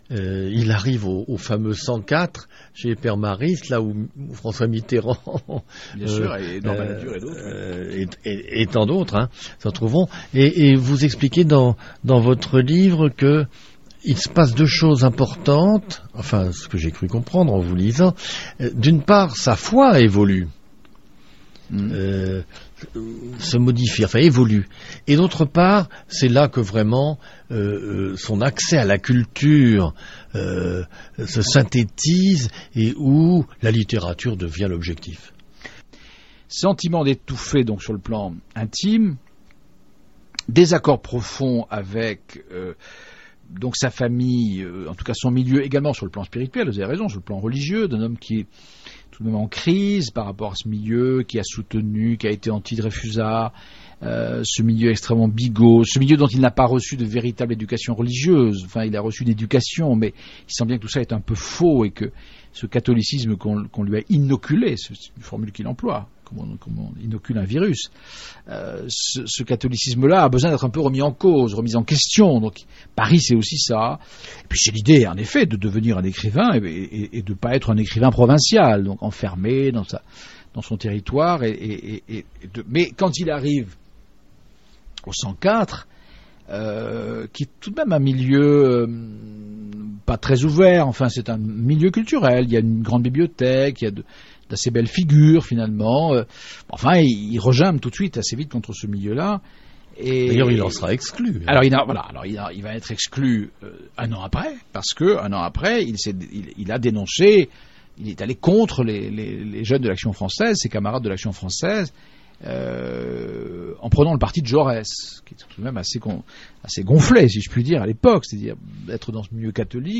Ce petit extrait est tiré d’une émission diffusée fin 2009 sur« Fréquence Protestante ».